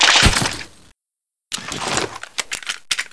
Index of /server/sound/weapons/ay69
ay69_reload.wav